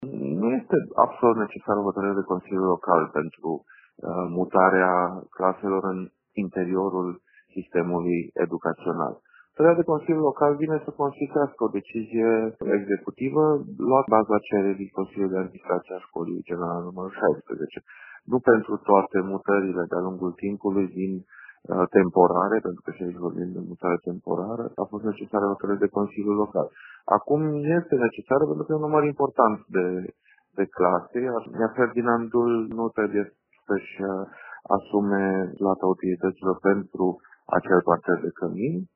Viceprimarul Timişoarei, Dan Diaconu, spune că hotărârea e necesară pentru a se şti clar cine plăteşte utilităţile.